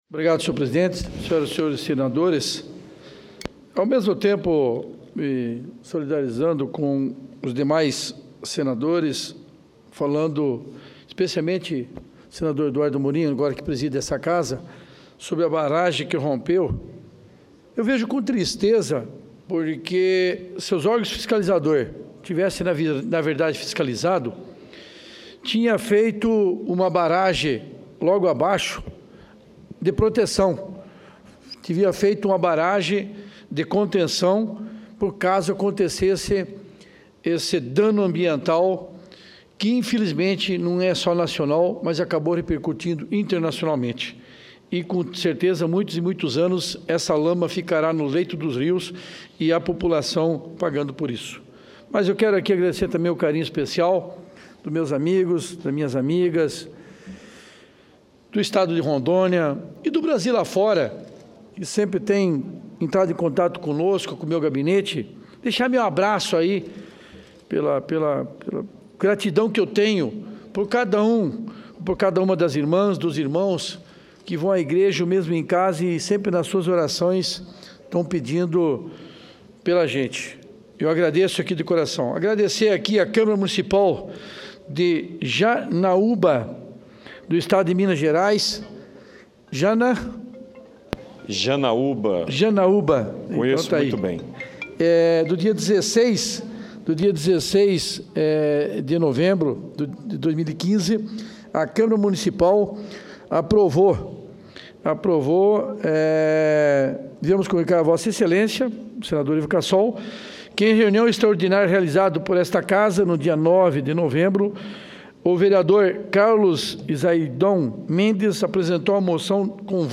Plenário 2015
Discursos